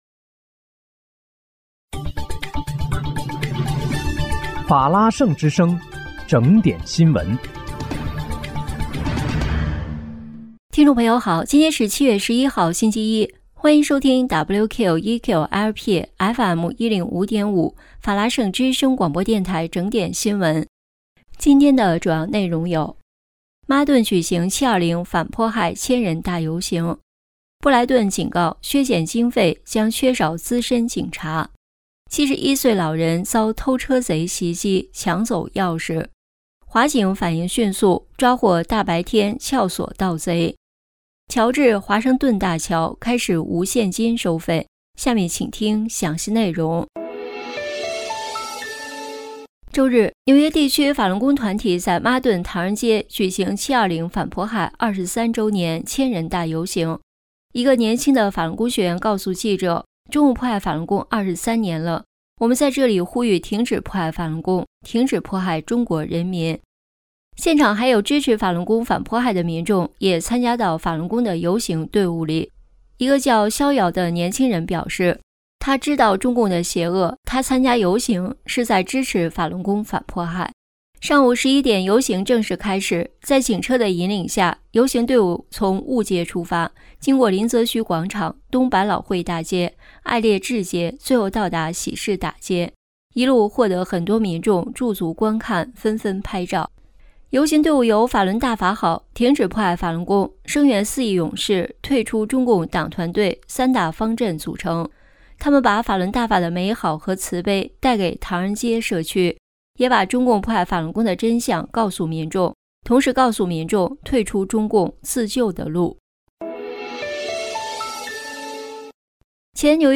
7月11日（星期一）纽约整点新闻